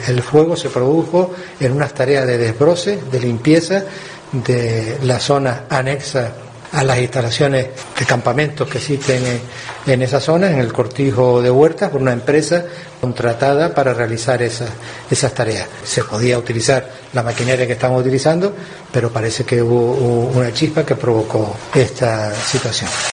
El presidente del Cabildo de Gran Canaria, Antonio Morales, explica el origen del incendio de Tejeda